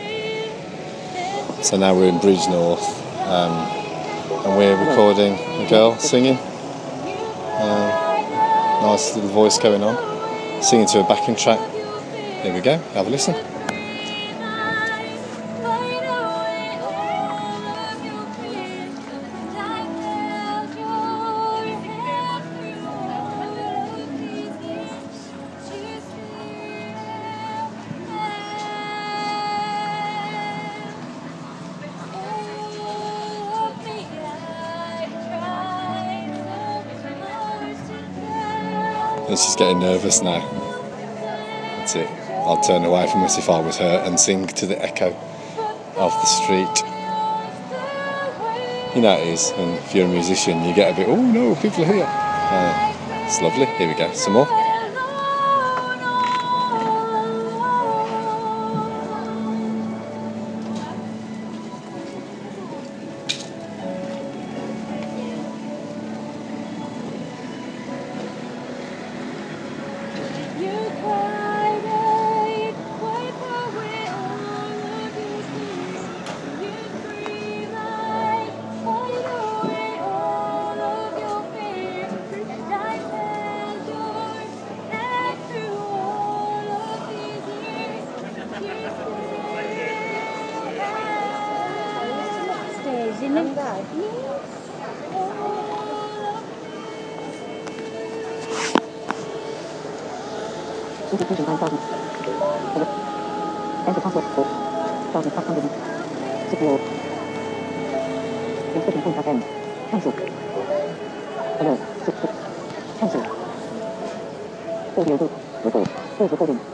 Singer at bridgenorth